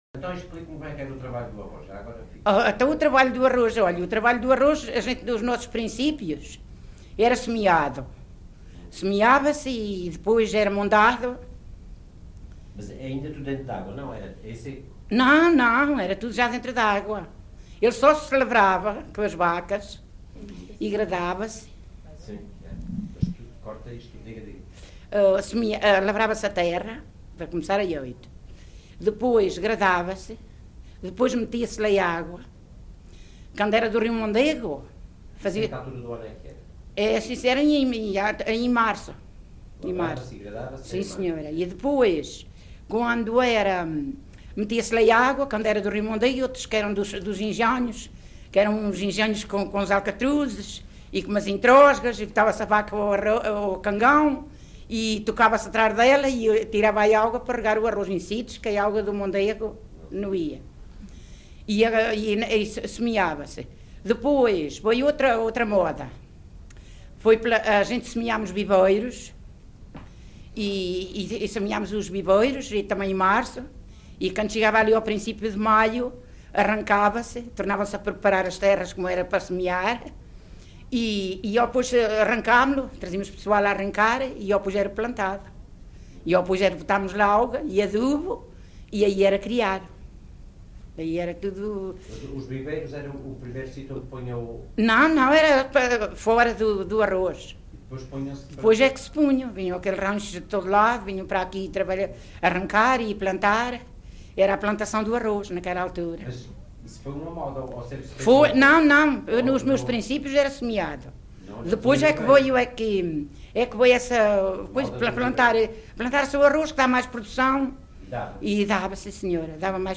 Vila Pouca do Campo, excerto 13
LocalidadeVila Pouca do Campo (Coimbra, Coimbra)